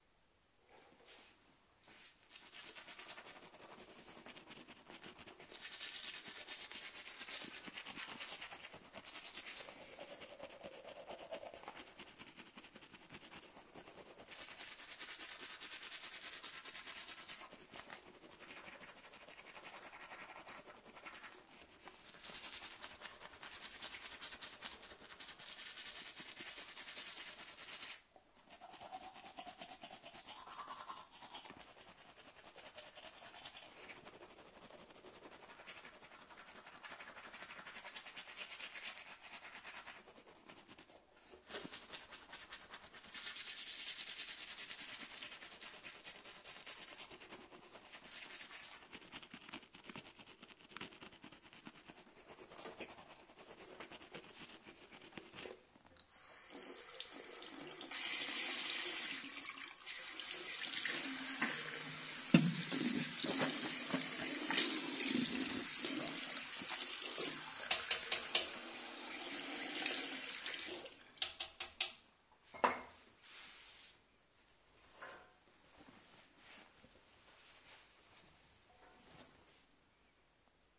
刷牙
描述：我刷牙。
Tag: 清洁 牙齿 随地吐痰 刷子 卫生 牙刷 牙齿 刷涂齿 水槽 浴室 刷牙